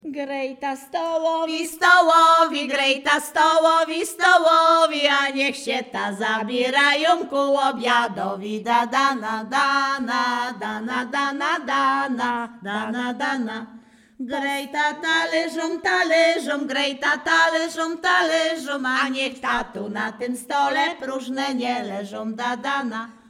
Śpiewaczki z Chojnego
województwo łódzkie, powiat sieradzki, gmina Sieradz, wieś Chojne
Weselna
miłosne weselne wesele przyśpiewki